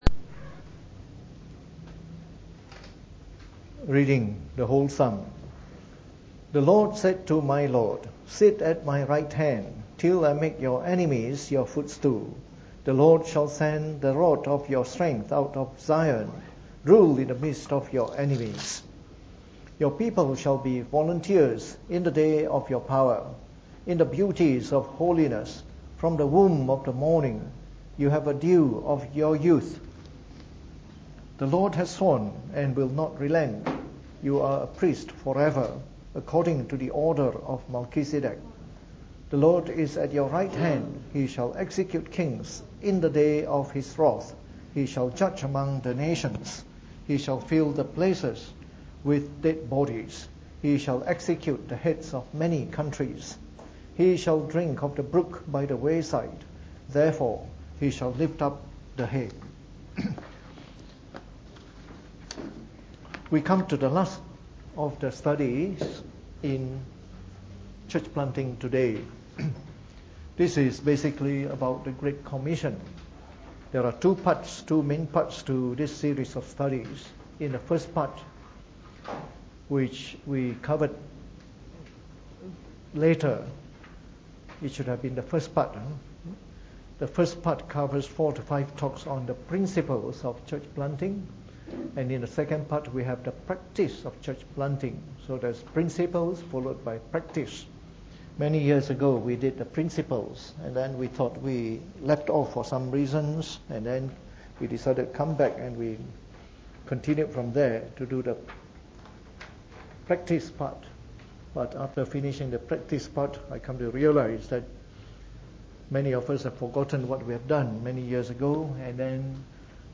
Preached on the 30th of August 2017 during the Bible Study, from our series on Church Planting Today.